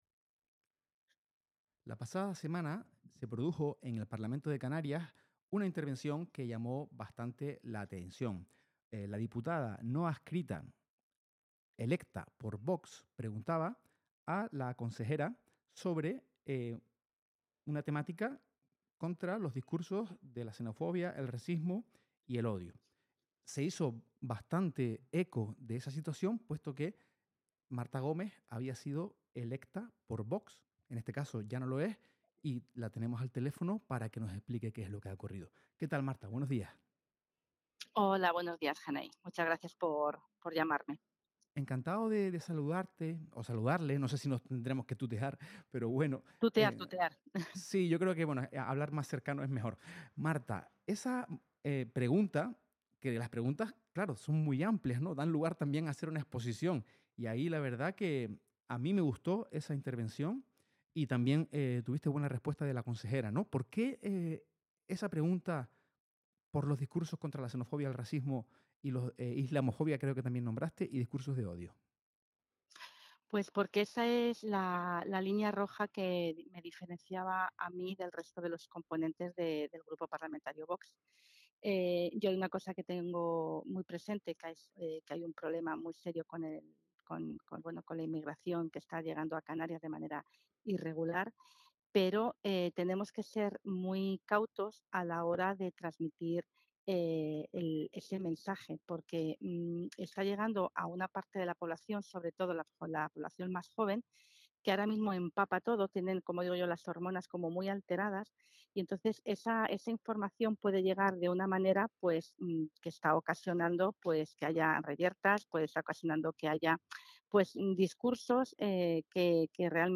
Entrevistas y declaraciones